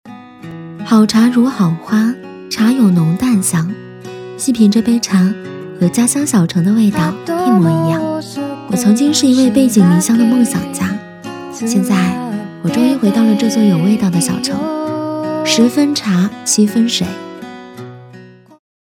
本合集收集了茶酒文化宣传配音类型的样音，供大家参考和挑选心仪的声音。
女B15-独白【茶叶】-年轻素人
女B15-独白【茶叶】-年轻素人.mp3